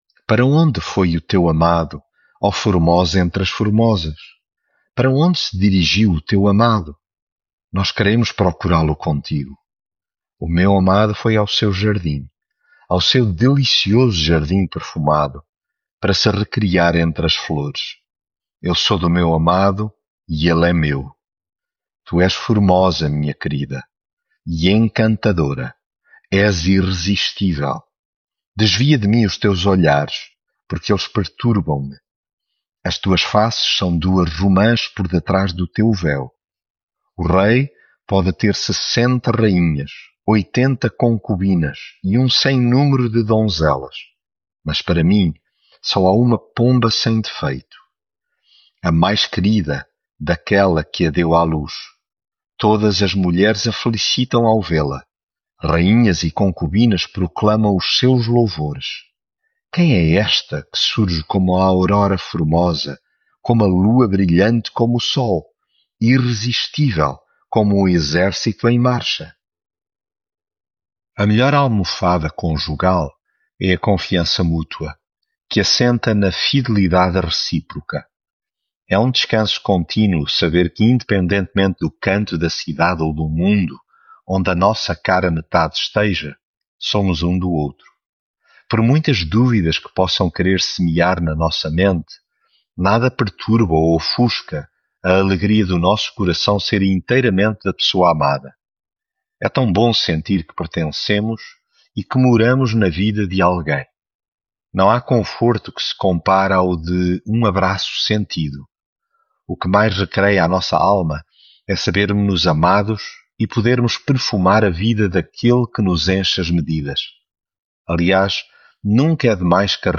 Devocional
Leitura em Cantares 6